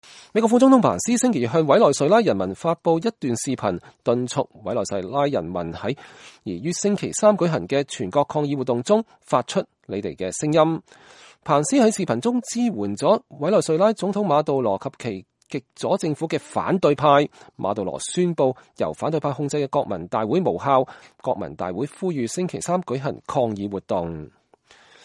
美國副總統彭斯向委內瑞拉總統馬杜羅的反對者發表視頻講話後接受傳媒採訪。(2019年1月22日)